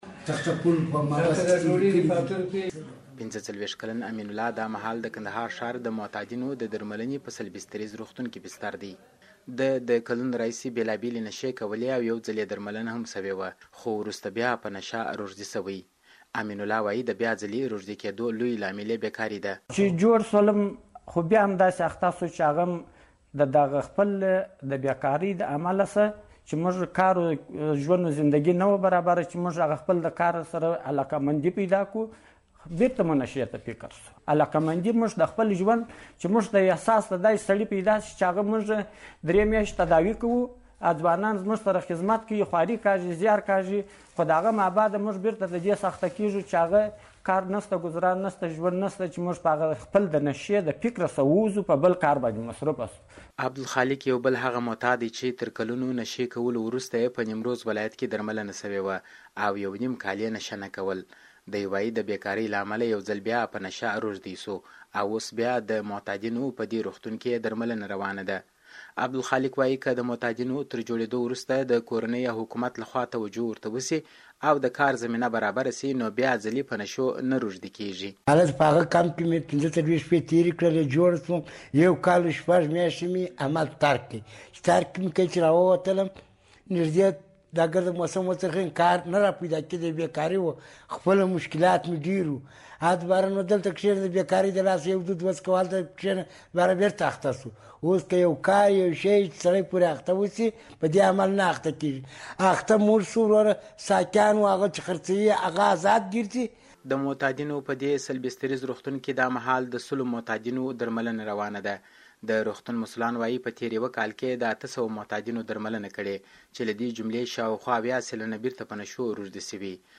د کندهار د معتادینو راپور